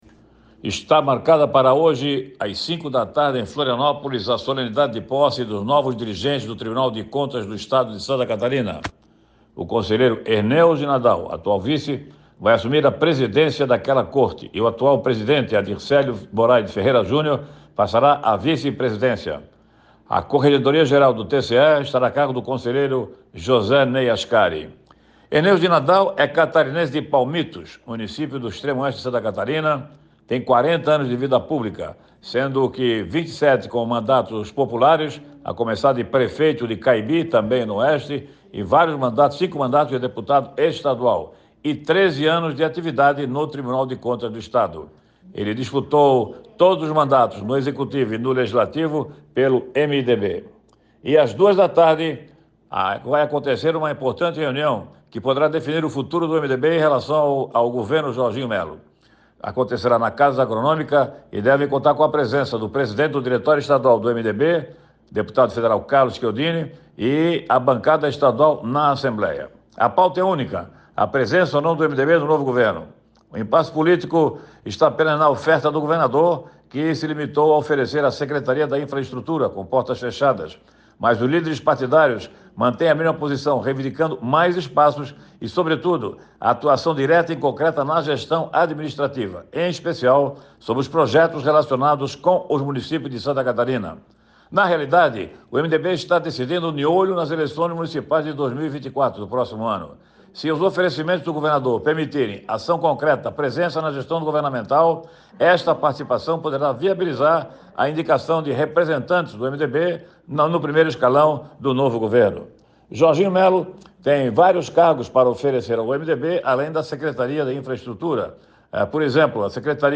Jornalista fala sobre a posse dos novos dirigentes da corte que ocorrerá nesta segunda-feira (13), às 17h. Outro destaque do comentário fica por conta da reunião do MDB com o governador do Estado